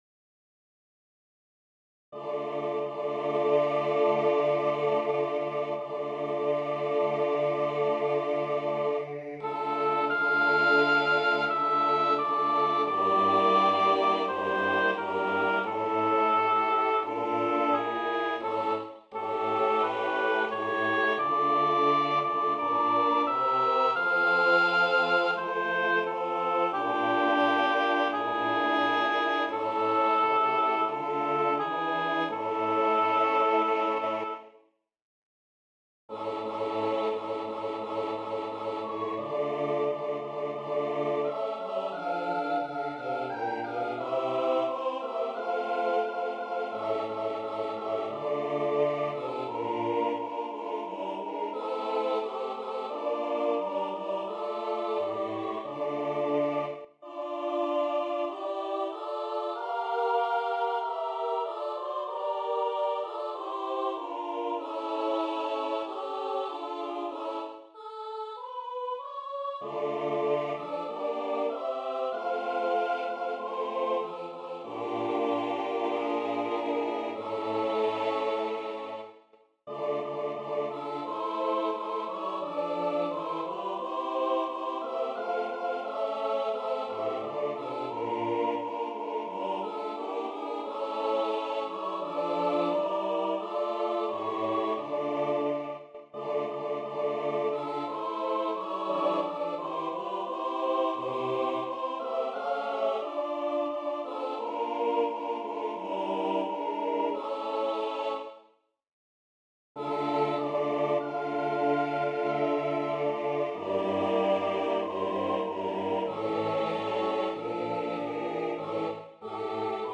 Для смешанного хора и солиста, a cappella